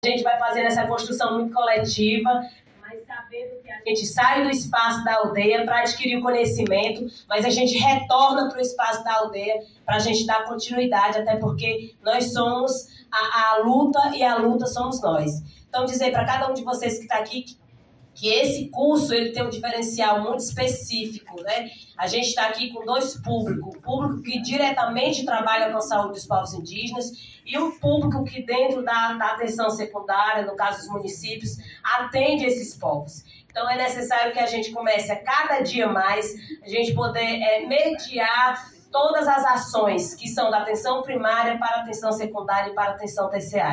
A secretária dos Povos Indígenas do Ceará, Juliana Alves, falou sobre a centralidade que a capacitação assume nos diferentes campos da saúde no estado.
SONORA-JULIANA-ALVES-2.mp3